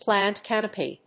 plant+canopy.mp3